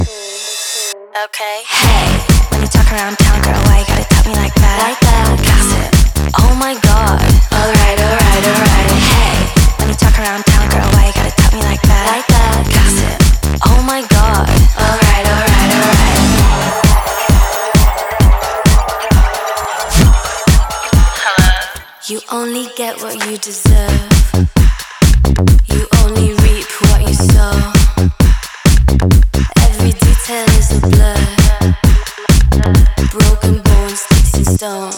Жанр: Танцевальные